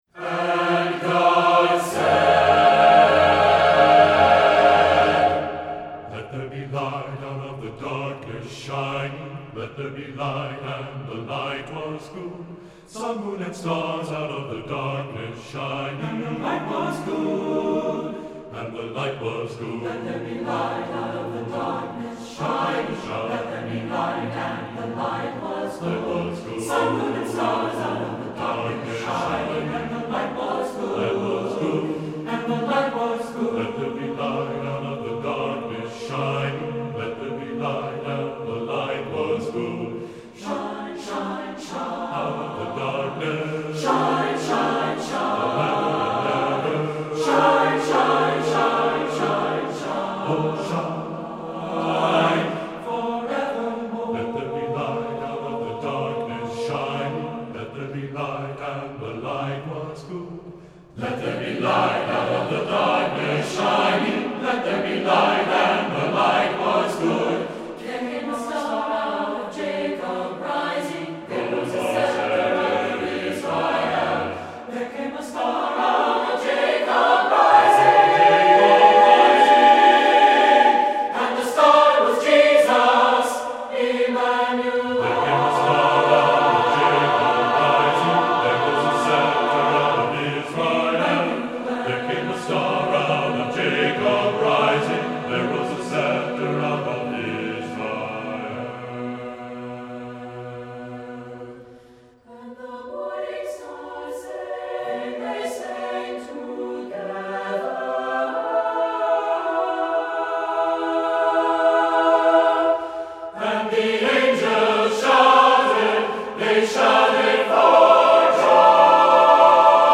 Voicing: TTBB